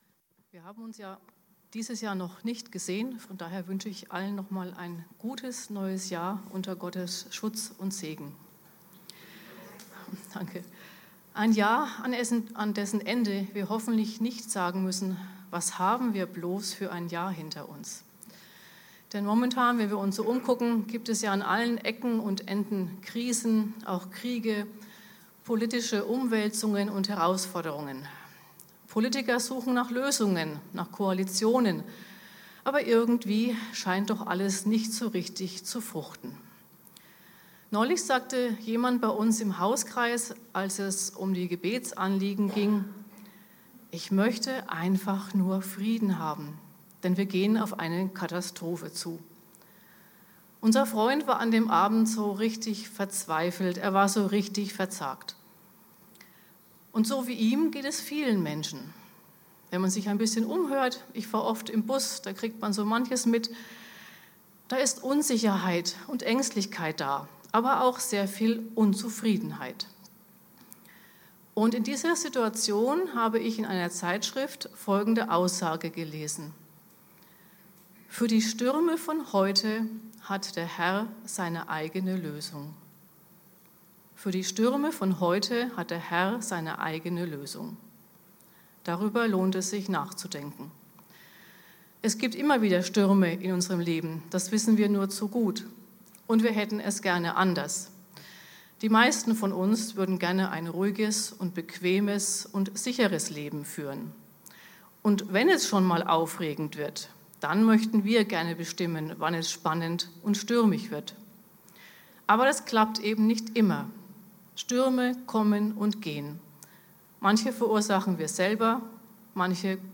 Predigt Glaubt ihr nicht